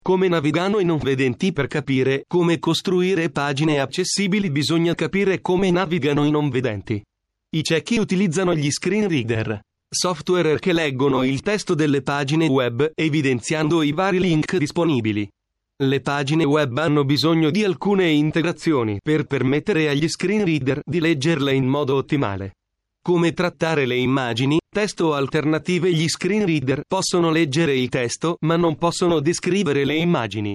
I Ciechi utilizzano gli Screen Reader: software che leggono il testo delle pagine web evidenziando i vari link disponibili.
Prova ad ascoltare come questi software leggono le pagine web.
(Software utilizzato: Actor 5)